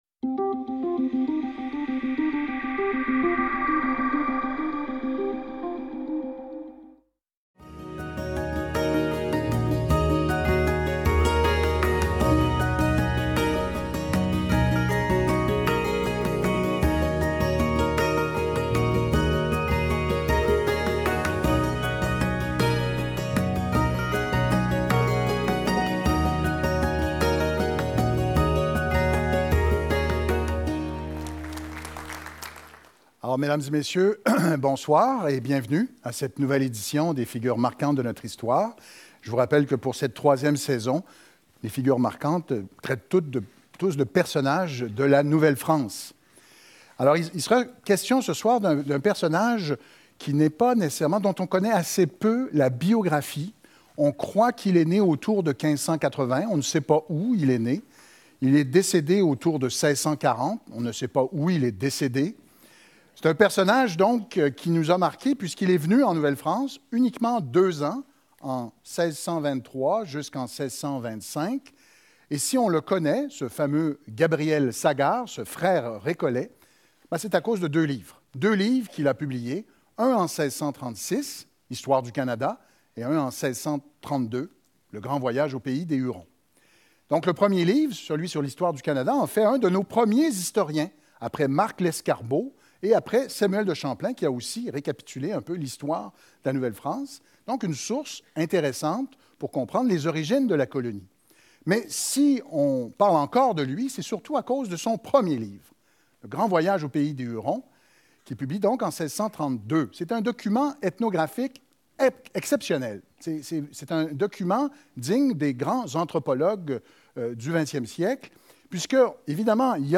historien et professeur Le récollet Gabriel Sagard séjourna pendant environ un an en Nouvelle-France